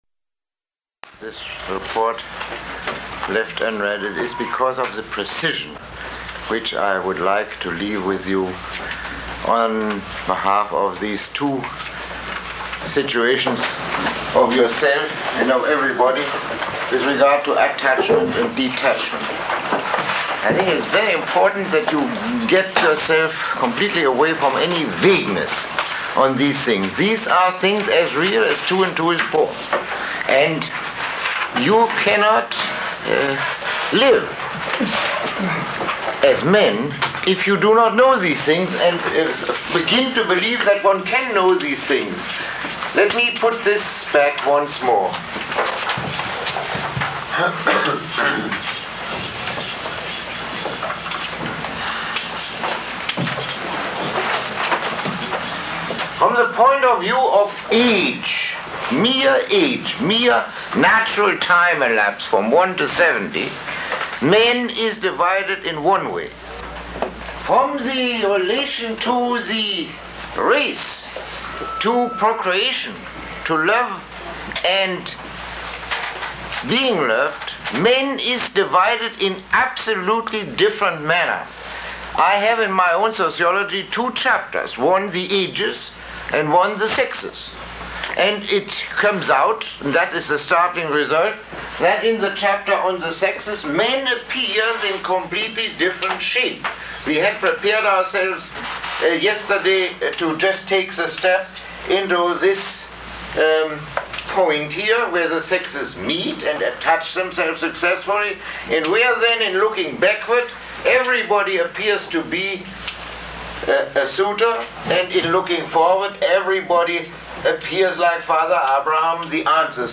Lecture 16